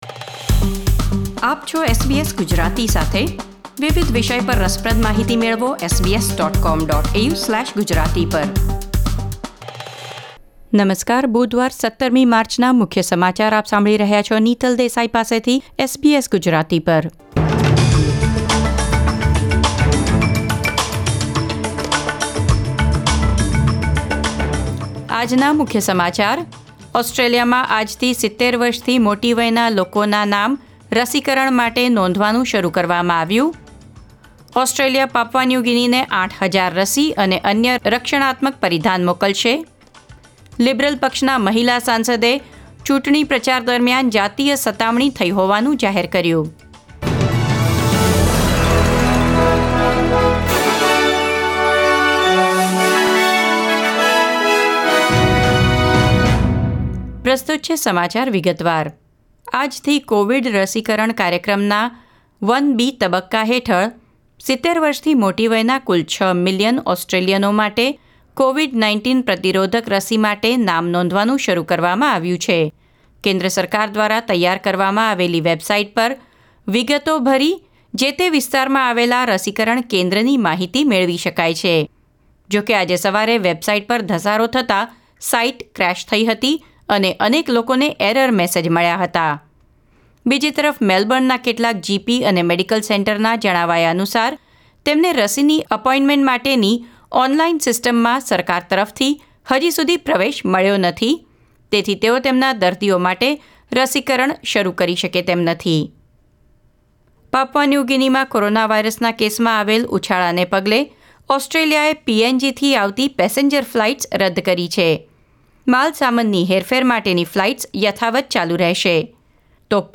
SBS Gujarati News Bulletin 17 March 2021